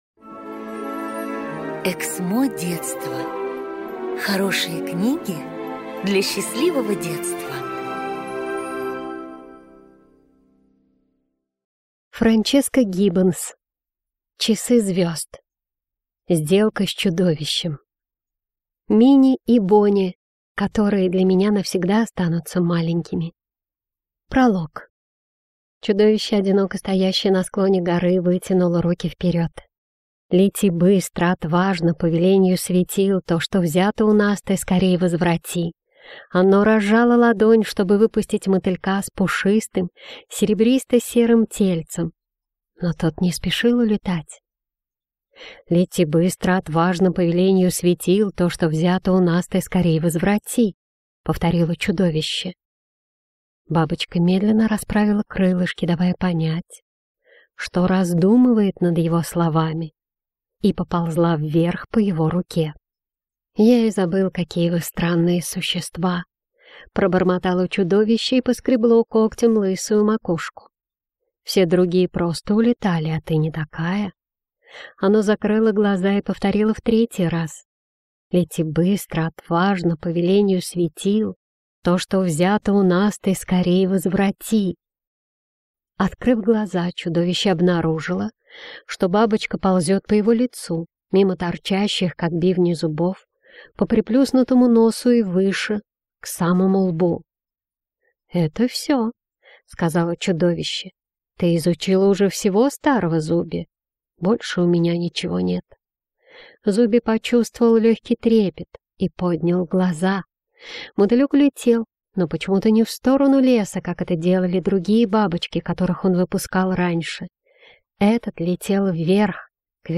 Аудиокнига Сделка с чудовищем | Библиотека аудиокниг
Прослушать и бесплатно скачать фрагмент аудиокниги